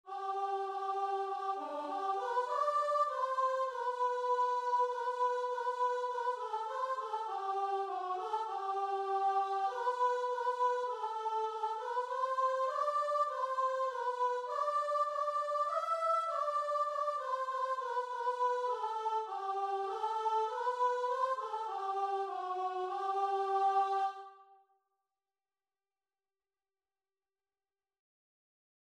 Free Sheet music for Guitar and Vocal
4/4 (View more 4/4 Music)
G major (Sounding Pitch) (View more G major Music for Guitar and Vocal )
Classical (View more Classical Guitar and Vocal Music)